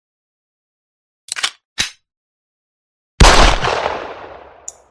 Free SFX sound effect: Fireworks.
Fireworks
# fireworks # celebration # bang # sfx About this sound Fireworks is a free sfx sound effect available for download in MP3 format.
540_fireworks.mp3